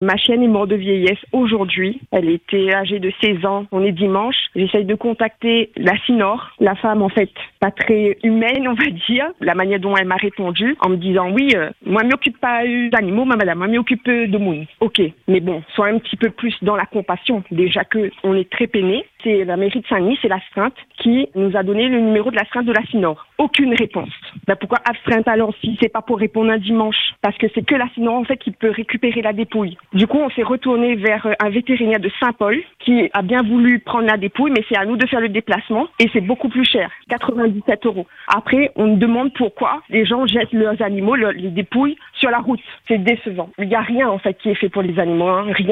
Un automobiliste raconte sa surprise et son incompréhension. Après 15 ans d’assurance sans problème, il se retrouve avec une panne… et impossible de se faire prendre en charge immédiatement.